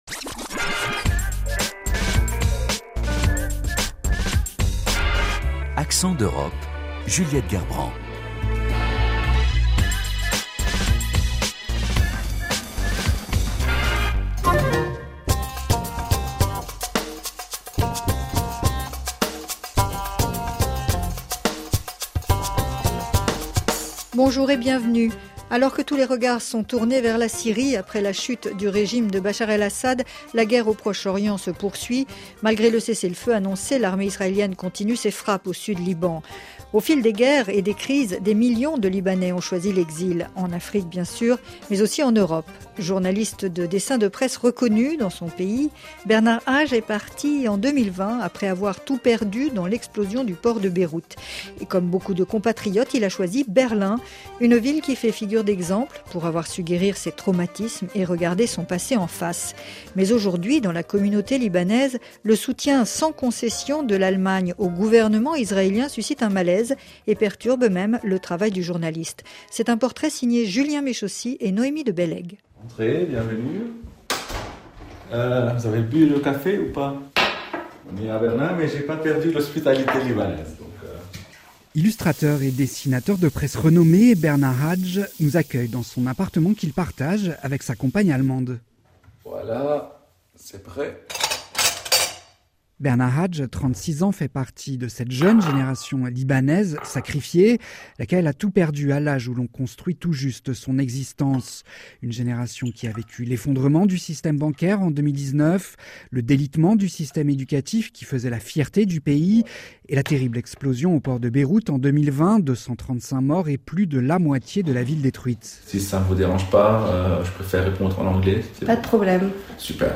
Considérée comme une référence par ses alliés de Fratelli d’Italia en Italie ou Républicains aux États-Unis, la politique familiale hongroise s’adresse à une frange restreinte de la population et coûte très cher à l’État ; le résultat n’est pas à la hauteur, le président l’a lui-même reconnu. Reportage.